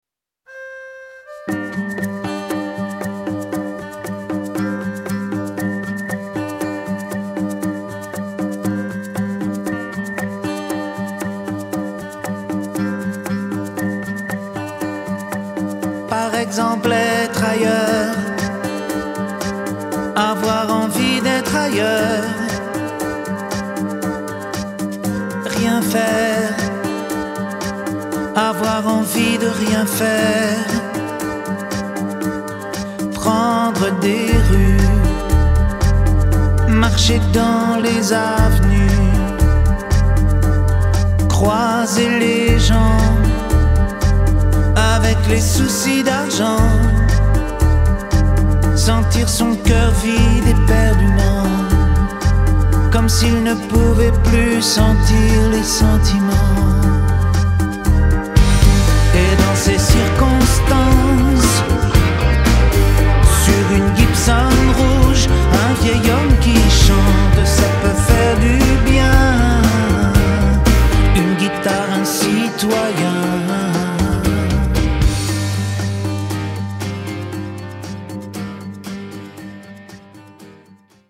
tonalité SIb majeur